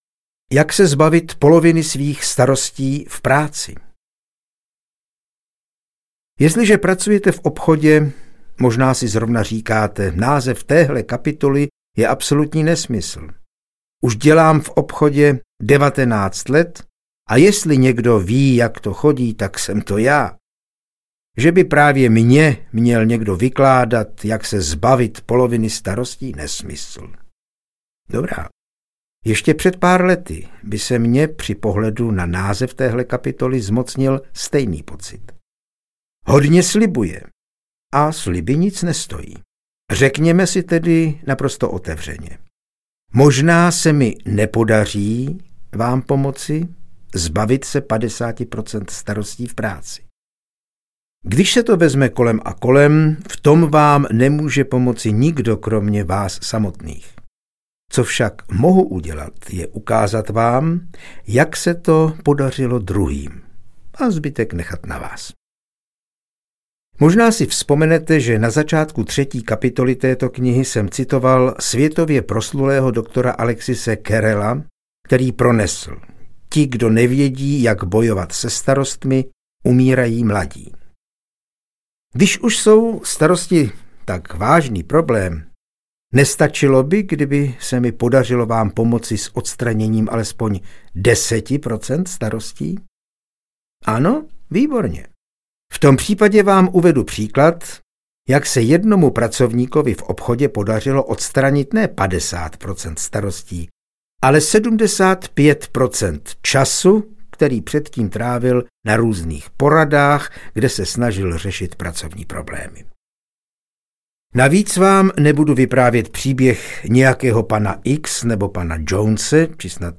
Jak se zbavit starostí a začít žít audiokniha
Ukázka z knihy